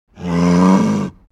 دانلود آهنگ مزرعه 14 از افکت صوتی طبیعت و محیط
دانلود صدای مزرعه 14 از ساعد نیوز با لینک مستقیم و کیفیت بالا
برچسب: دانلود آهنگ های افکت صوتی طبیعت و محیط دانلود آلبوم صدای مزرعه روستایی از افکت صوتی طبیعت و محیط